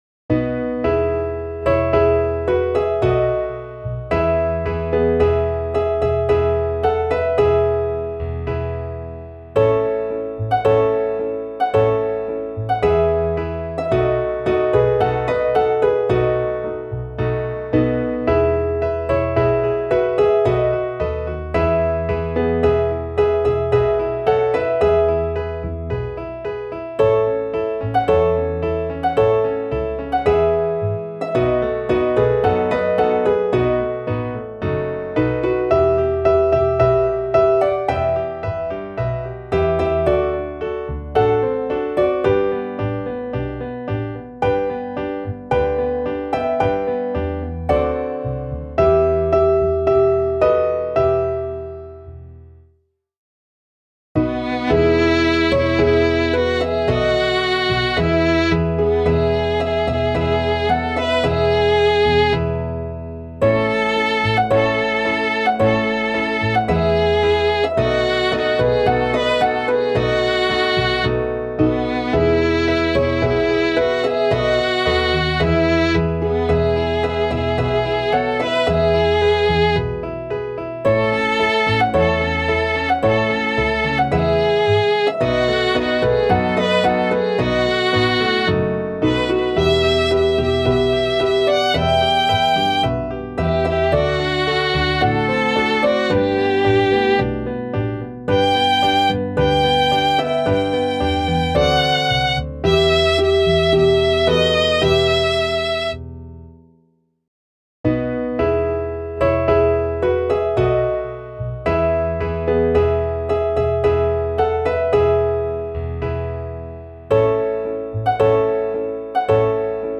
Klaviersatz